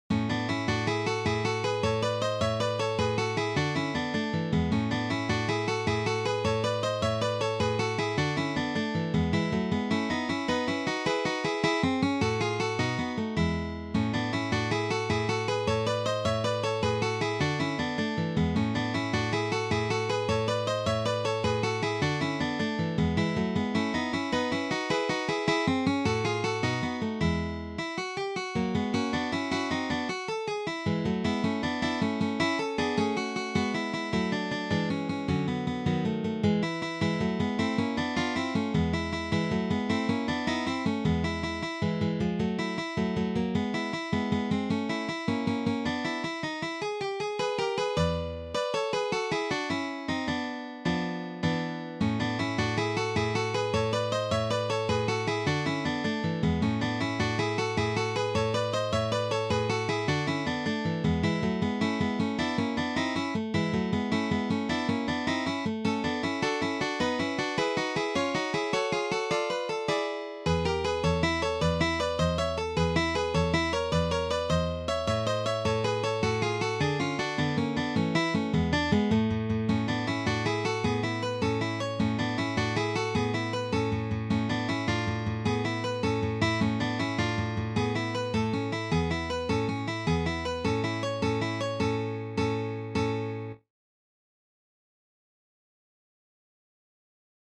arrangement for 3 guitars
The fast tempo makes this piece particularly challenging.